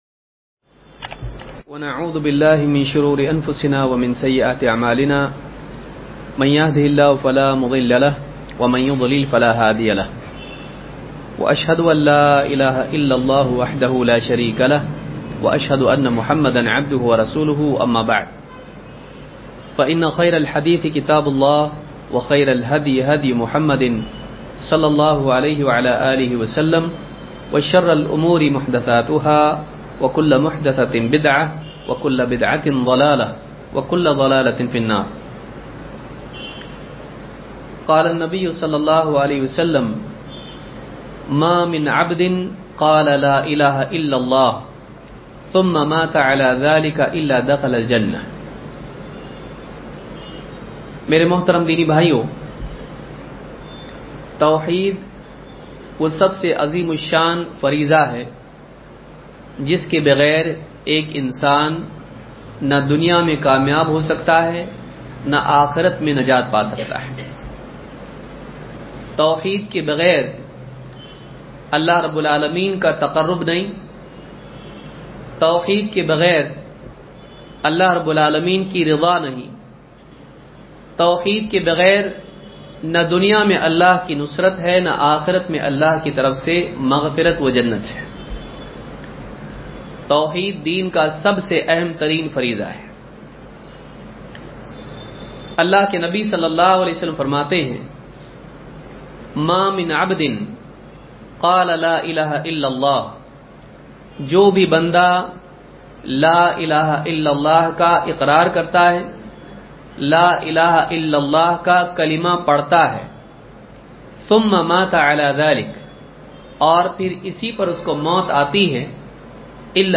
La Ilaaha Illallah Ki Qabuliyat Ki Shartein Dars-01.mp3